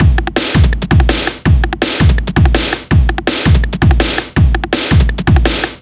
丛林鼓声循环2
标签： 165 bpm Drum And Bass Loops Drum Loops 1 002.42 KB wav Key : Unknown
声道立体声